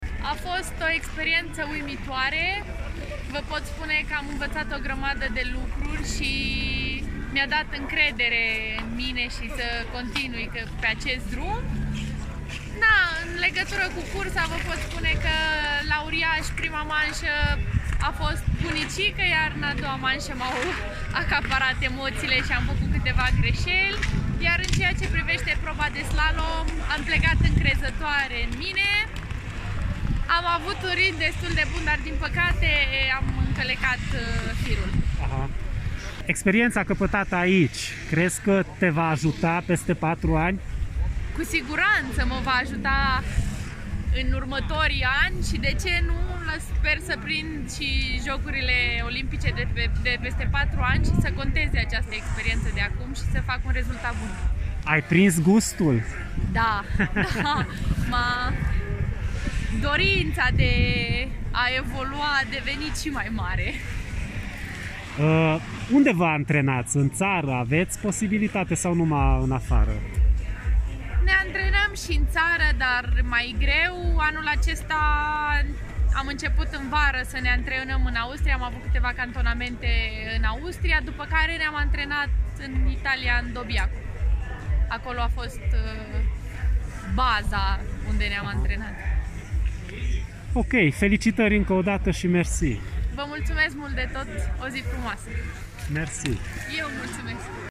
Un interviu audio cu Maria Constantin